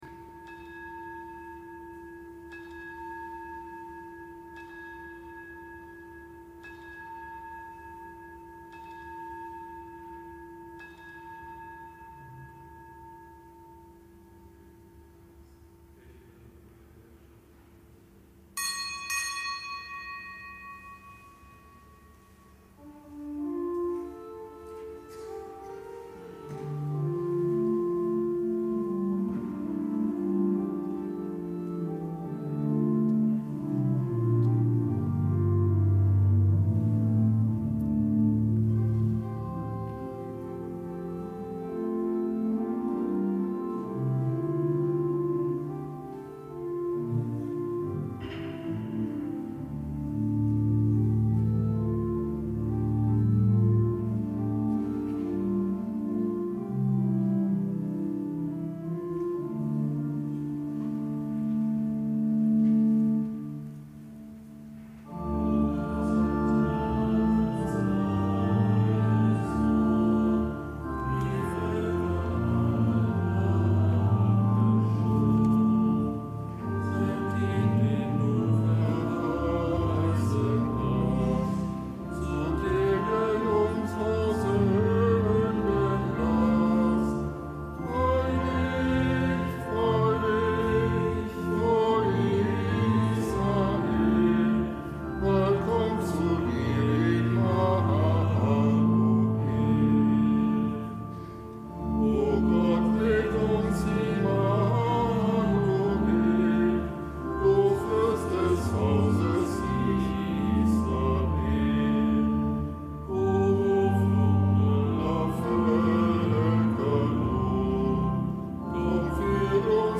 Kapitelsmesse am Dienstag der vierten Adventswoche